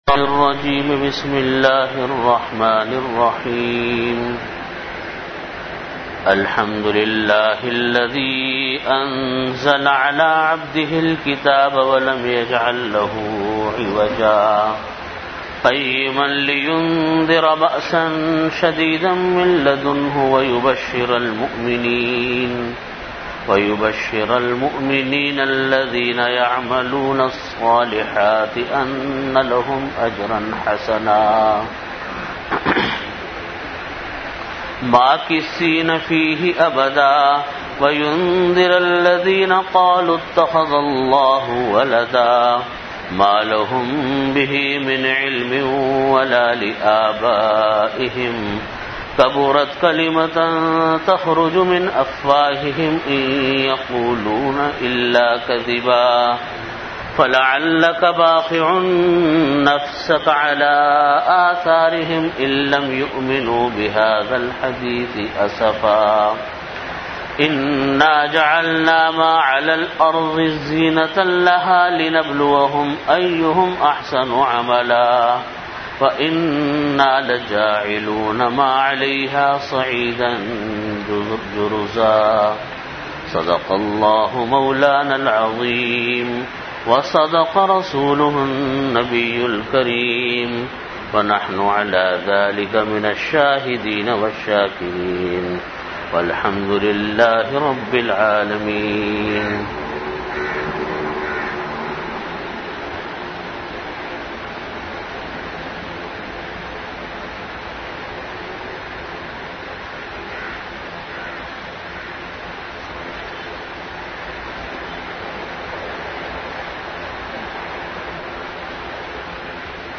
Audio Category: Bayanat
Time: After Asar Prayer Venue: Jamia Masjid Bait-ul-Mukkaram, Karachi